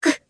Kara-Vox_Damage_jp_01.wav